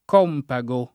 campago [k#mpago] s. m. (stor.); pl. -gi — sòrta di calzare degli antichi romani — anche compago [